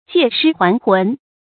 借尸還魂 注音： ㄐㄧㄝ ˋ ㄕㄧ ㄏㄨㄢˊ ㄏㄨㄣˊ 讀音讀法： 意思解釋： 迷信傳說；人死以后靈魂可以借別人的尸體復活。